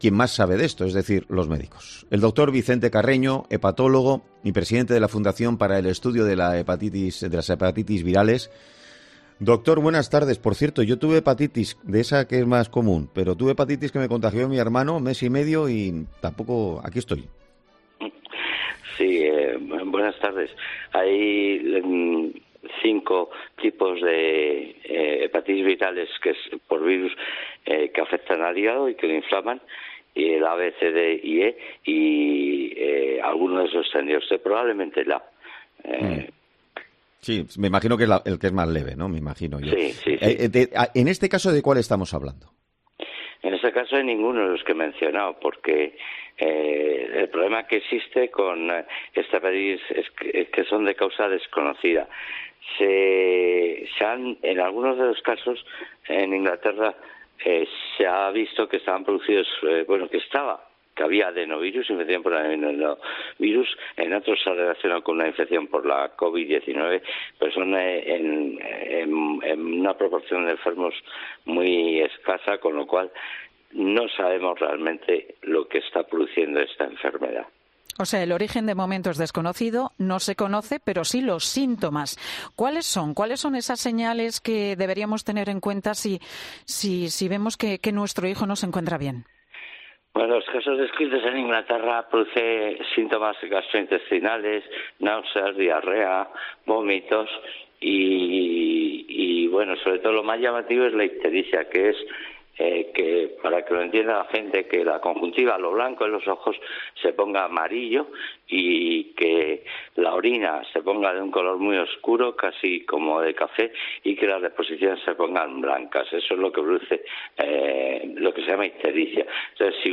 Quizá te suenen y es que, según el doctor, a día de hoy conocemos hasta "cinco tipos de hepatitis virales, por virus que afectan e inflaman al virus", estas serían las hepatitis "A, B, C, D y E". Aunque, en este caso, "no hablamos de ninguno de los mencionados. Son de causa desconocida", es la incógnita con la que comienza su entrevista el doctor, que rápidamente nos ofrece todas las claves.